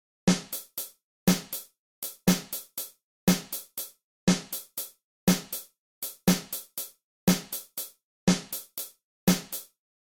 标签： 120 bpm Rap Loops Drum Loops 1.68 MB wav Key : Unknown
声道立体声